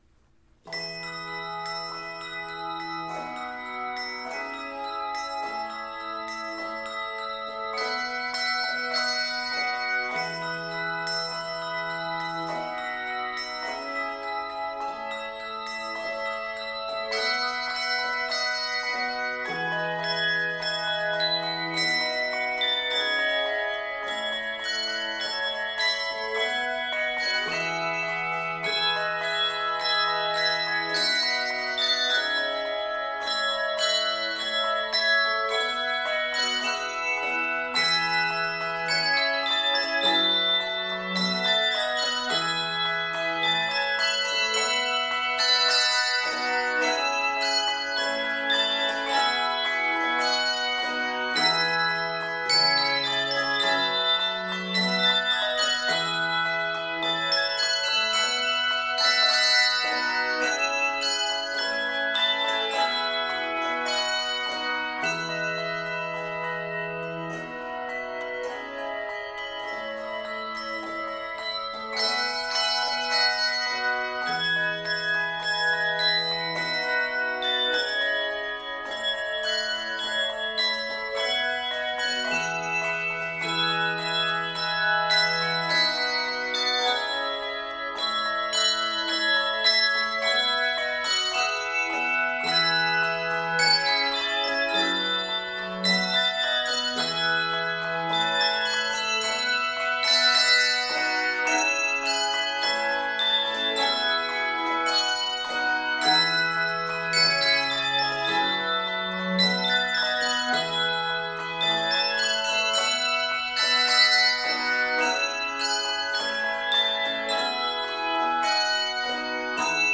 Keys of E Major and F Major.